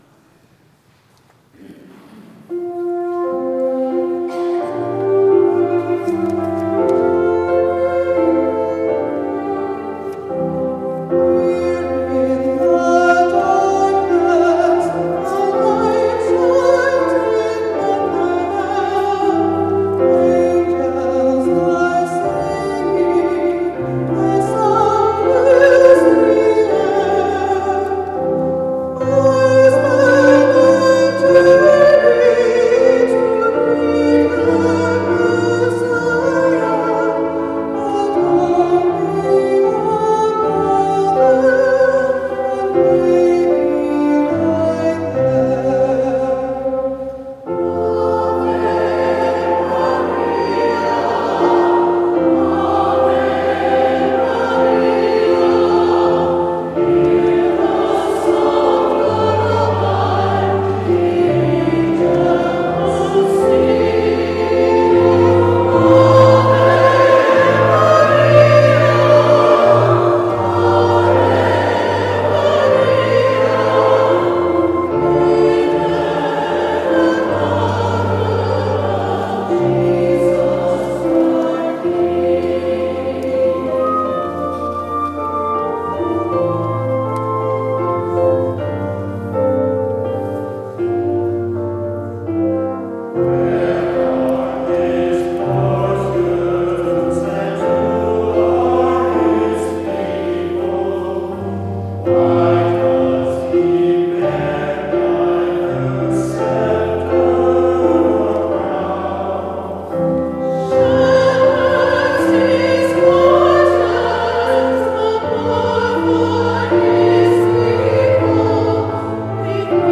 December 7, 2025 - St. Philomena Christmas Concert
2025 St. Philomena Chorus